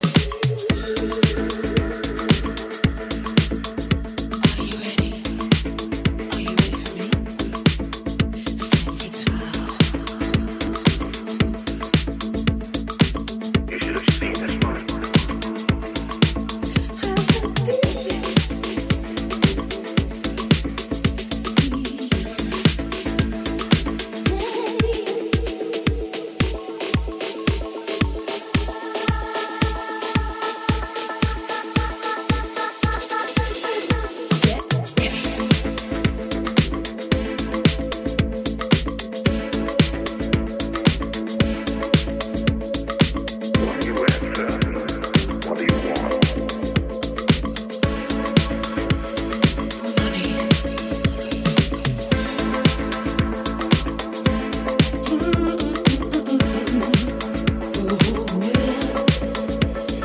ethereal vocals
hypnotic synth riffs